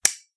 switch3.ogg